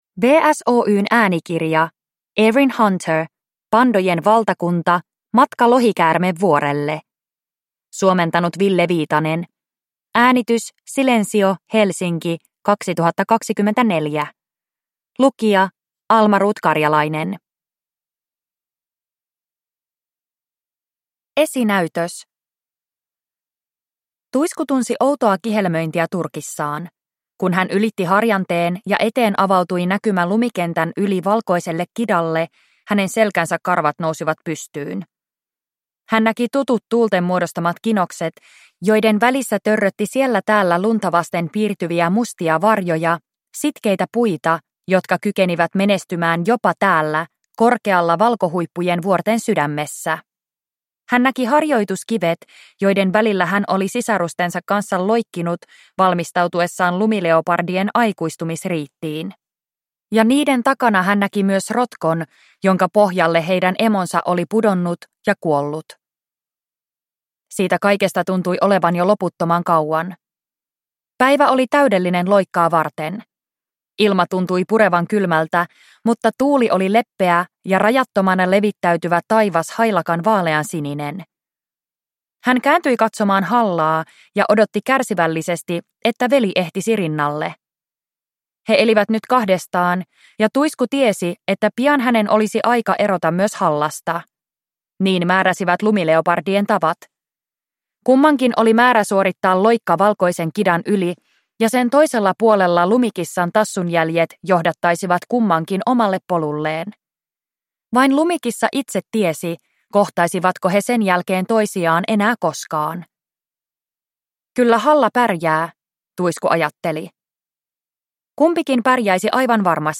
Pandojen valtakunta: Matka Lohikäärmevuorelle – Ljudbok